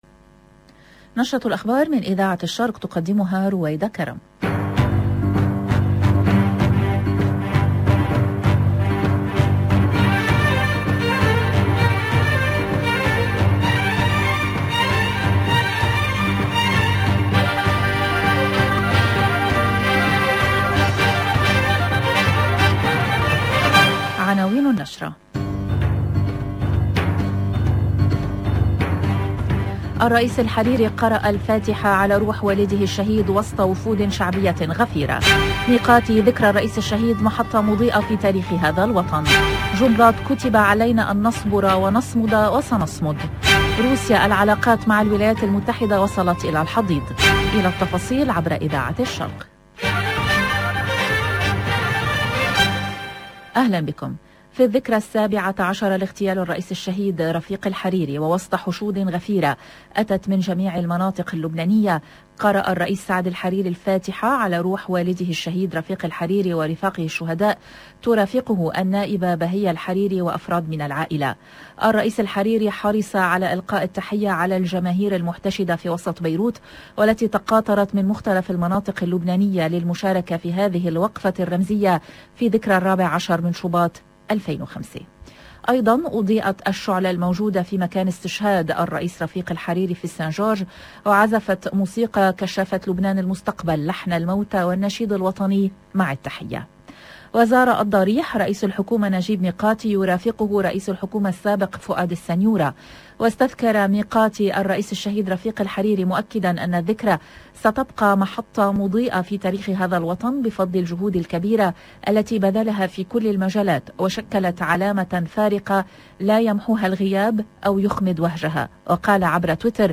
JOURNAL EN LANGUE ARABE